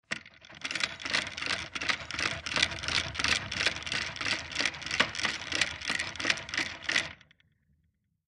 Old british desk telephone, hand crank magneto ringer (no bell)